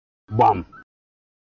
Gbam – Comedy Sound Effect
funny comedy Sound Effect No Copyright RMtv
Short, punchy, and instantly recognizable, this sound has become a favorite tool for creators who want to deliver humor with perfect timing.
Gbam-Comedy-sound-effect.mp3